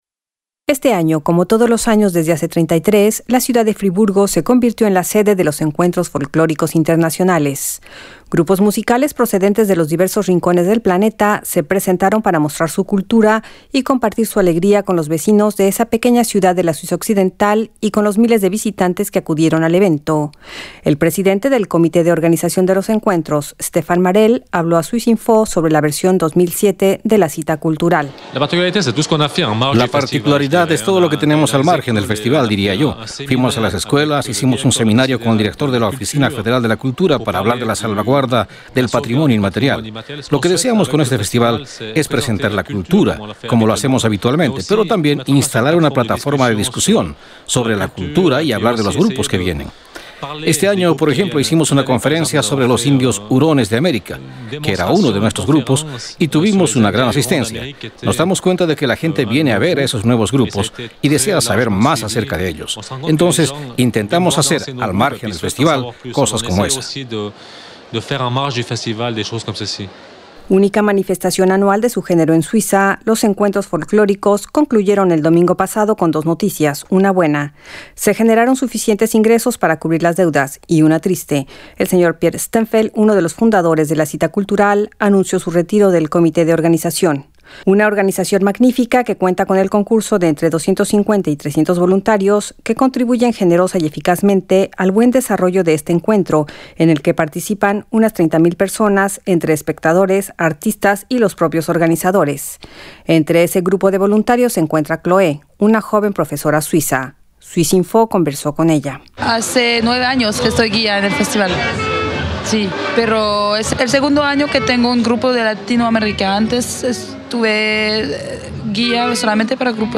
En su XXXIII edición, los Encuentros Folclóricos Internacionales de Friburgo se ratificaron como un vector cultural único de su género en Suiza. swissinfo conversó con diversos participantes en ese evento que año con año reúne a suizos con artistas procedentes de diversos países del mundo.